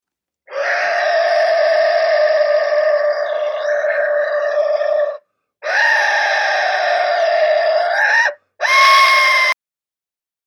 Large Aztec Death Whistle Ancient Mesoamerican Instrument shiny black
This Large Aztec Death Whistle, hand tuned to produce the most frightening scariest sound.
The Large Aztec Death whistle is a hand crafted musical instrument producing the loudest, scariest, terrifying sound around.  This instrument  is constructed the same way as the original archeological piece; with rare black clay and all natural appearance enhancer.